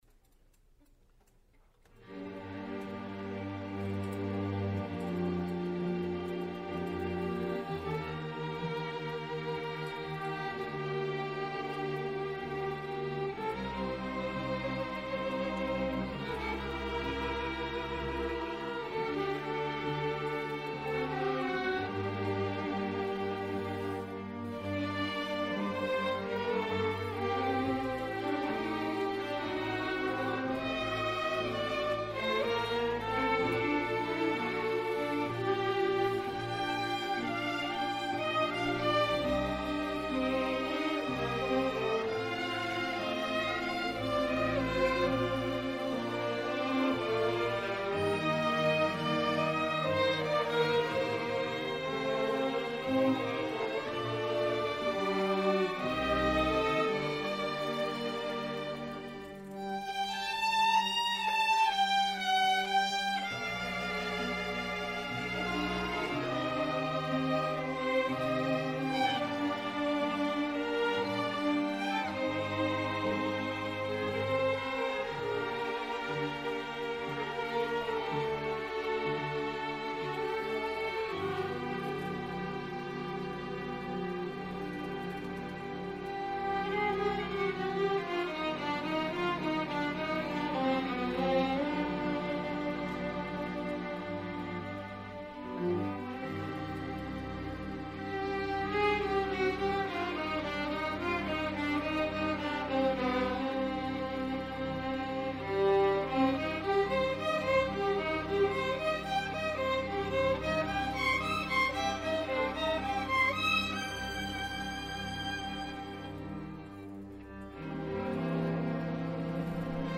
Queen City Community Orchestra
Fall 2022 Concert